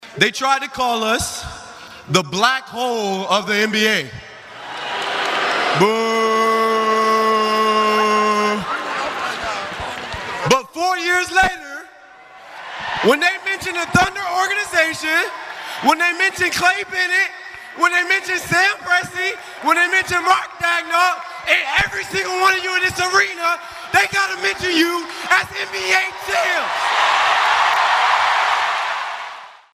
The celebration started with speeches and a rally at the Paycom Center. The best speech of the bunch came from Thunder reserve Aaron Wiggins.